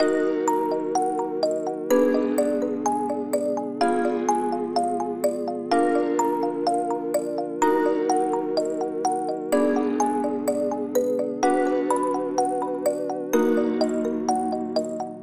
Tag: 126 bpm Trap Loops Bells Loops 2.56 MB wav Key : D